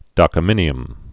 (dŏkə-mĭnē-əm)